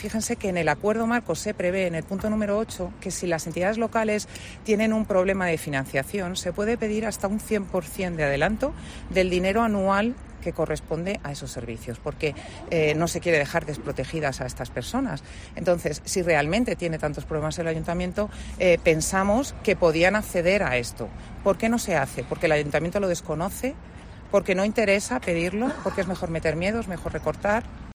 María José Samboal, concejal de PP. Ayuda a domicilio